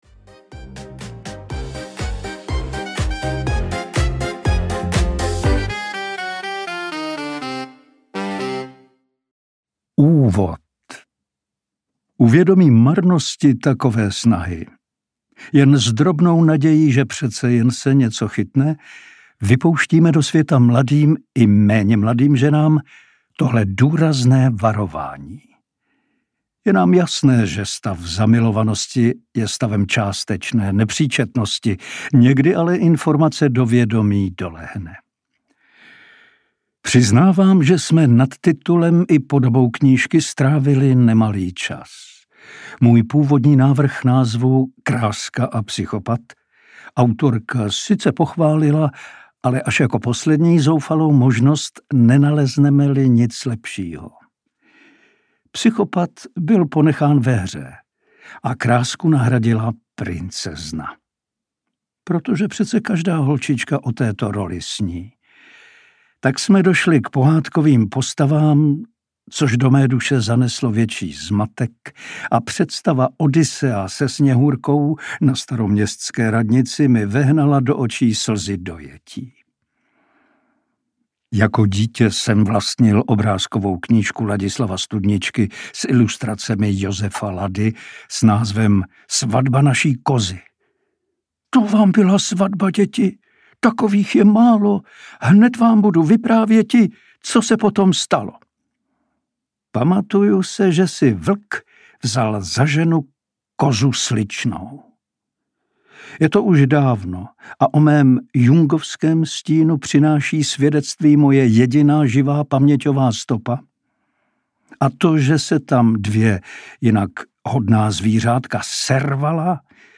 Princezna a psychopat audiokniha
Ukázka z knihy
• InterpretIrena Budweiserová, Pavel Soukup